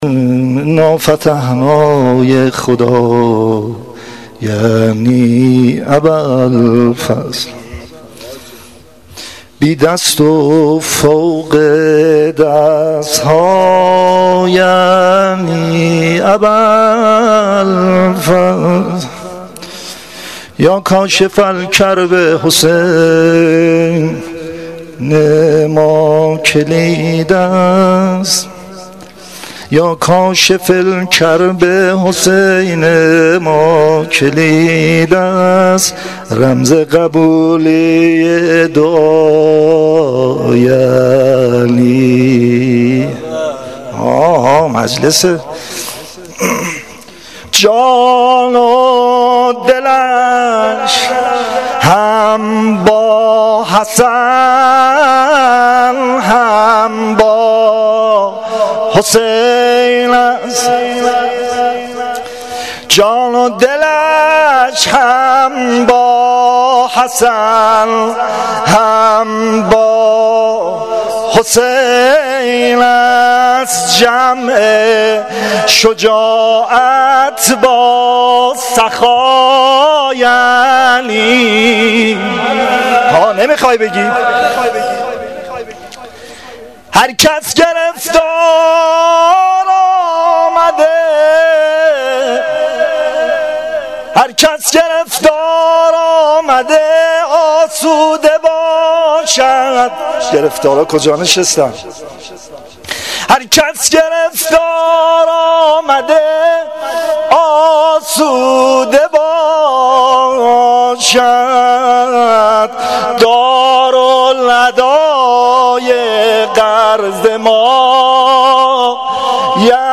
روضه شب نهم محرم الحرام 1396 (شب تاسوعا) متاسفانه مرورگر شما، قابیلت پخش فایل های صوتی تصویری را در قالب HTML5 دارا نمی باشد.